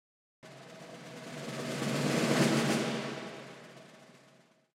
Couleur orchestrale sombre et grave : le crescendo avec des percussions
1) Les caisses claires :
J’ai d’abord posé un sample de crescendo suivi par 3 flas (un fla est un coup de caisse claire avec les 2 baguettes en léger décalage). Comme je trouvais le son de crescendo pas assez consistant, je l’ai bêtement doublé par un roulement de tambours :
crescendo_sombre1.mp3